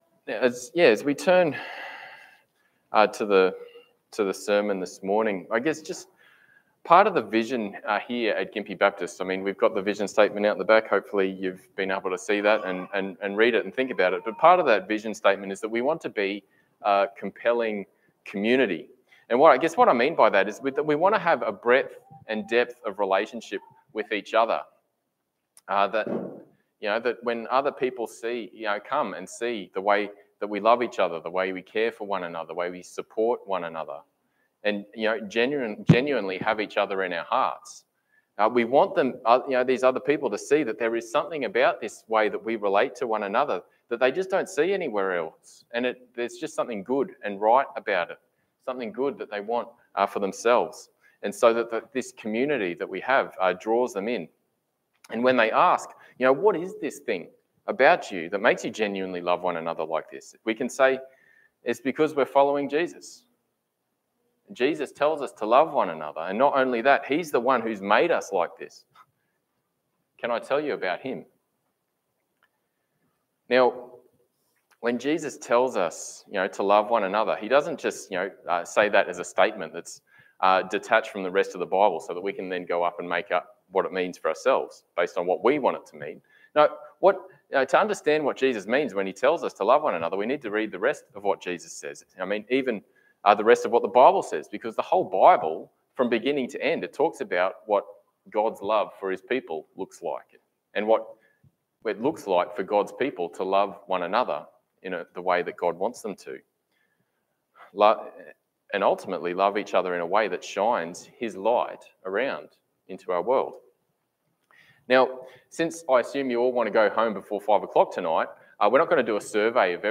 Passage: Galatian 6:1-10 Service Type: Sunday Morning « Knowing God Reaching the GBC Vision